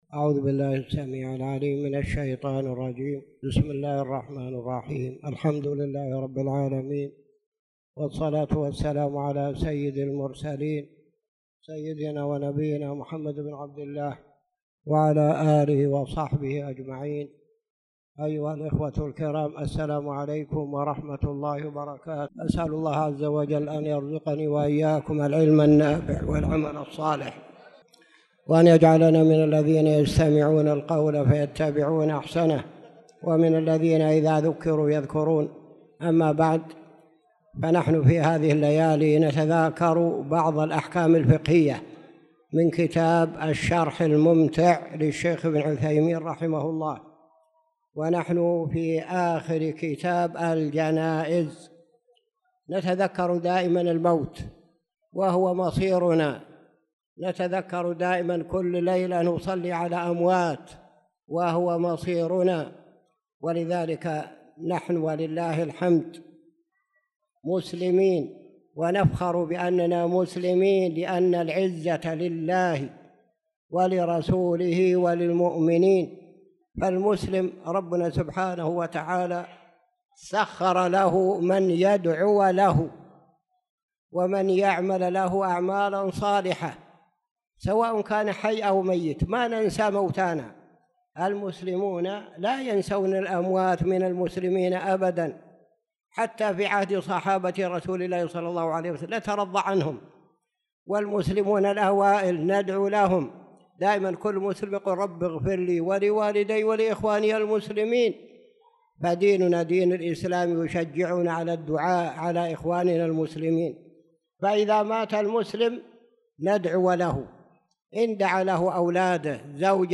تاريخ النشر ١٤ ذو القعدة ١٤٣٧ هـ المكان: المسجد الحرام الشيخ